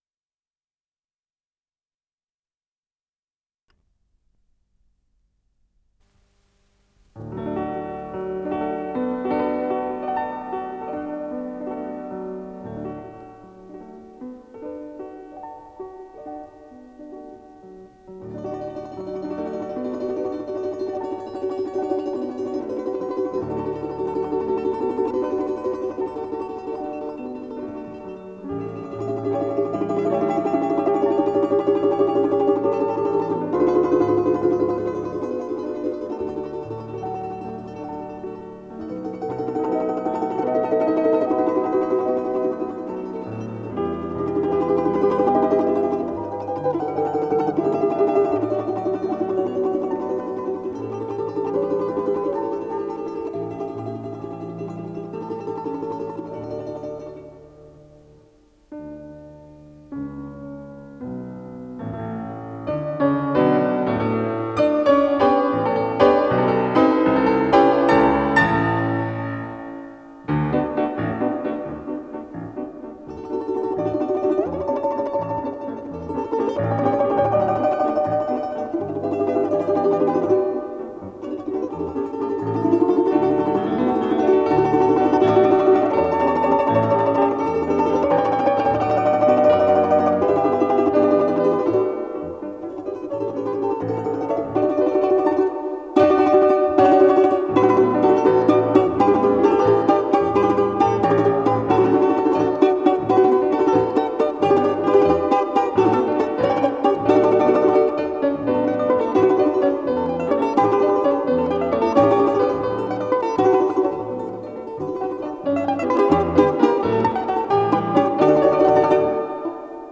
с профессиональной магнитной ленты
балалайка
фортепиано